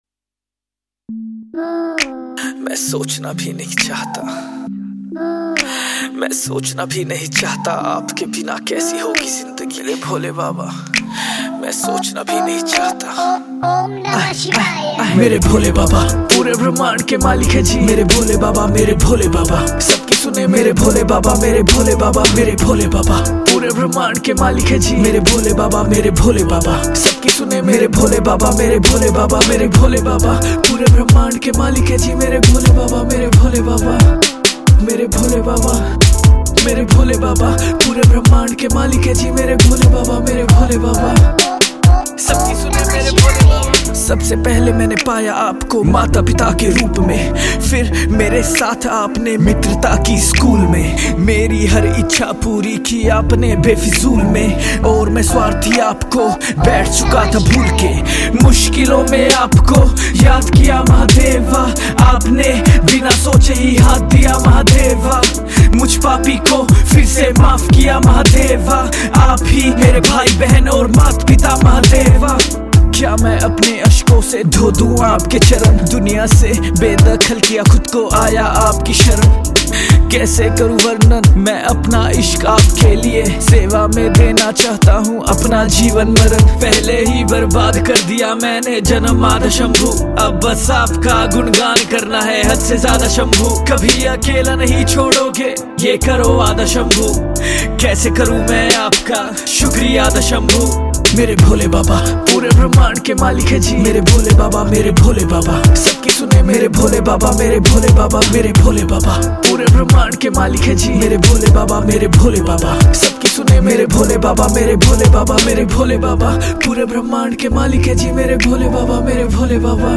Pop Songs